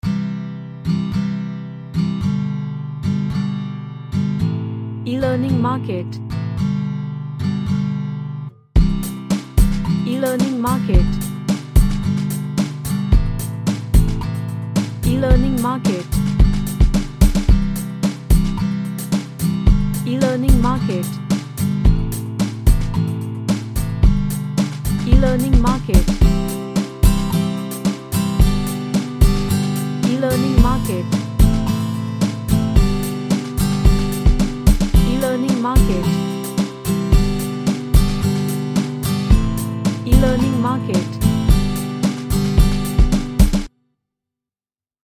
An acoustic upbeat track with acoustic guitar.
Upbeat